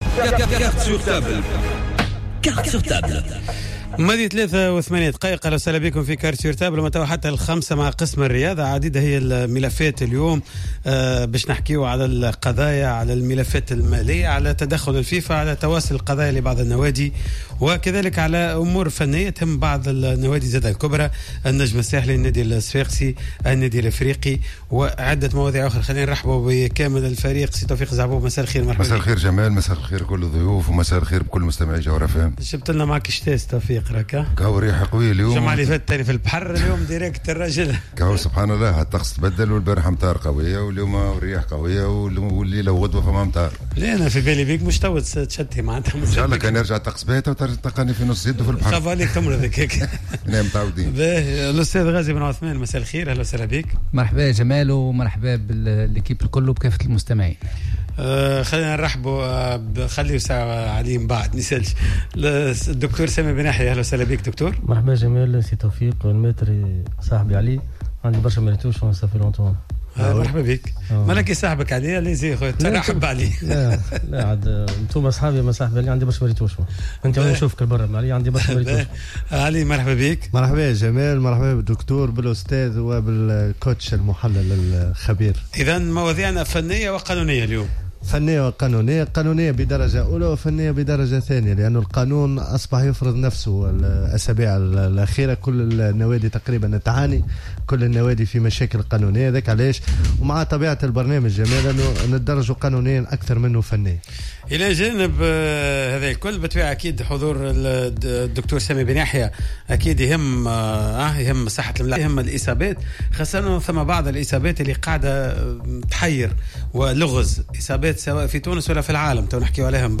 كما تدخل هاتفيا